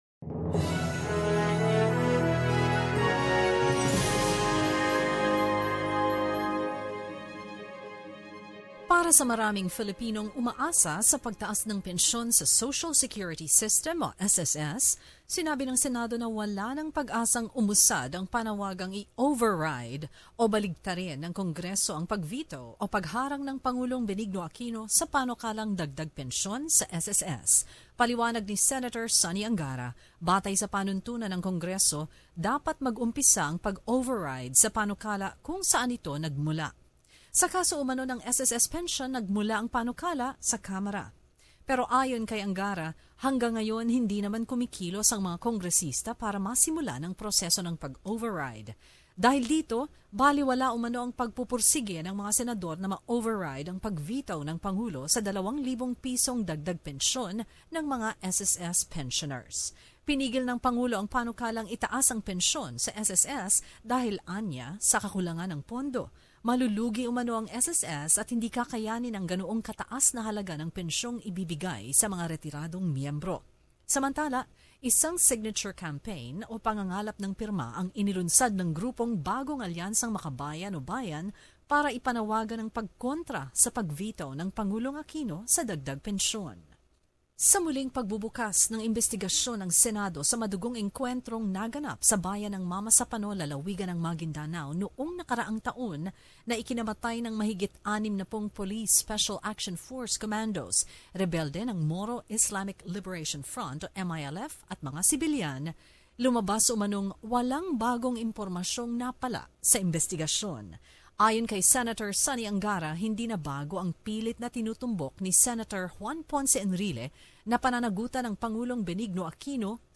The latest news from the Philippines